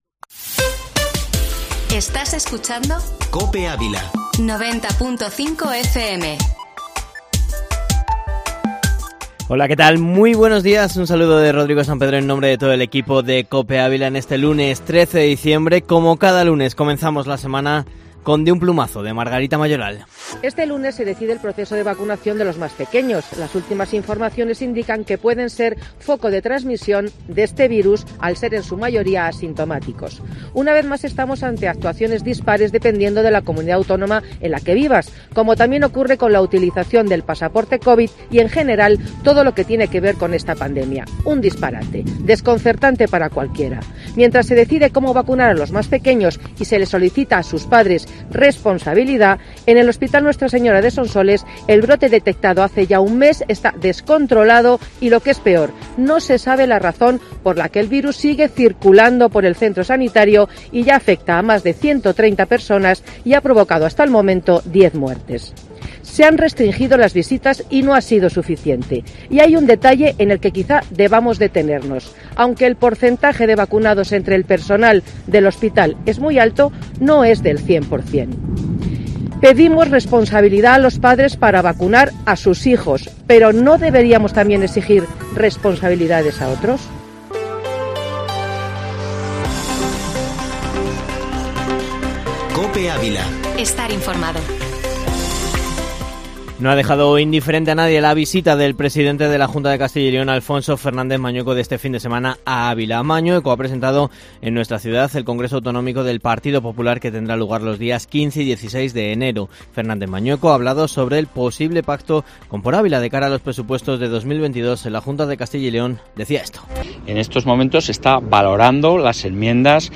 Informativo Matinal Herrera en COPE Ávila 13-dic